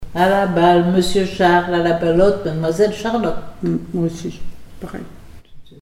formulette enfantine
comptines et formulettes enfantines
Pièce musicale inédite